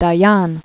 (dä-yän), Moshe 1915-1981.